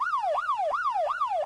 WEEOO1.ogg